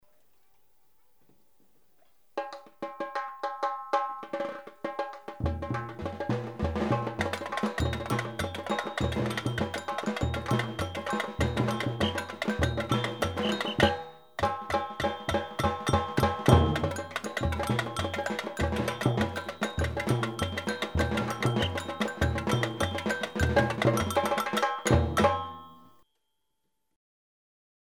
Ressources Batucada
Site d'audios et de partitions sur les percussions br�siliennes jou�es dans une batucada.